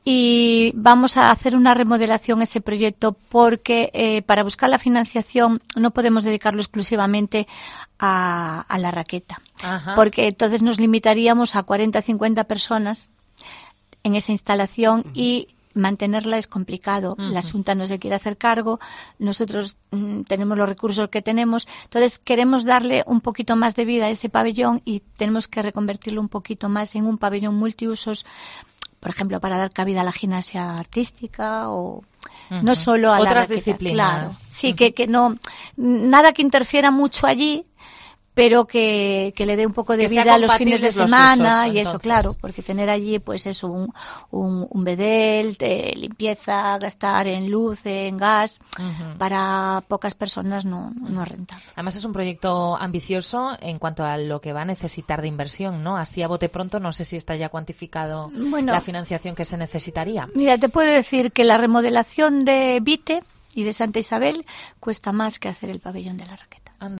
Esther Pedrosa reconoce en COPE Más Santiago que el Ayuntamiento compostelano dispone del proyecto y del solar, entre el estadio de San Lázaro y el vial periférico, la SC-20.
Hay que tener en cuenta, confiesa en la entrevista la concejala, que mantener esas instalaciones es complicado: "La Xunta no se quiere hacer cargo y nosotros tenemos los recursos que tenemos".